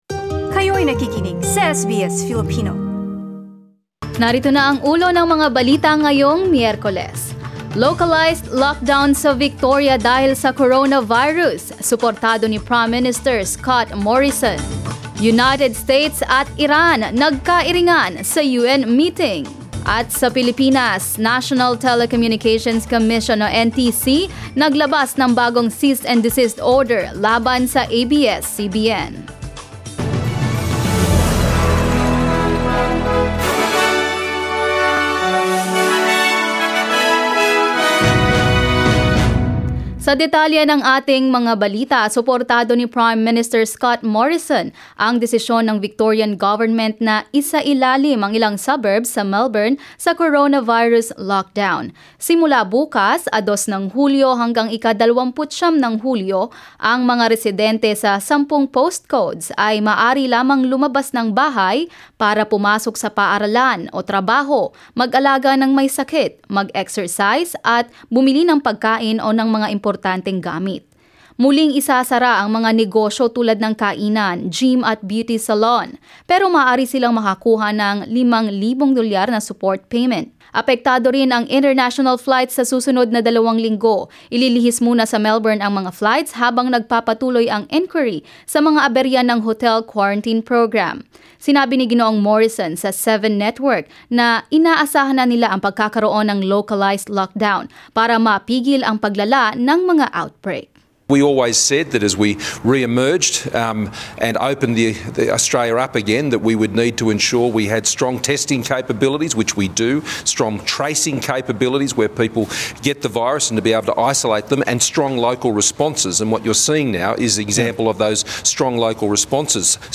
SBS News in Filipino, Wednesday 1 July